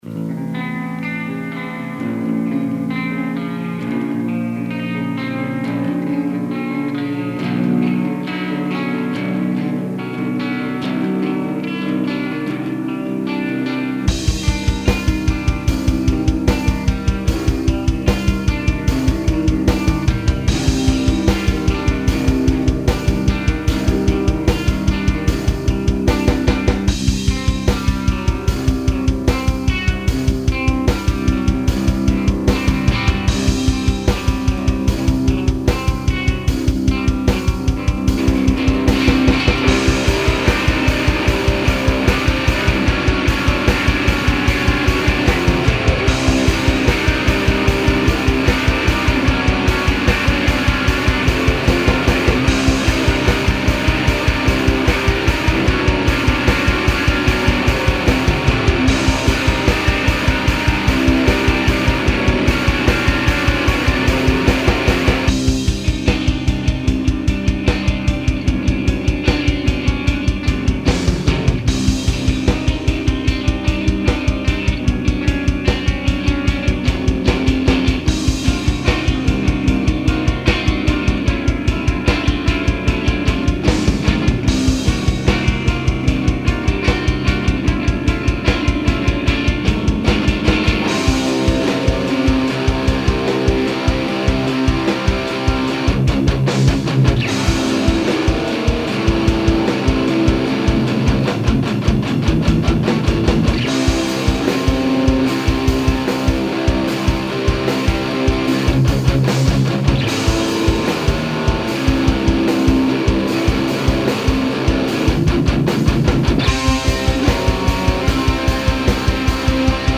dissonance experimental atmospheric instrumental noise ambient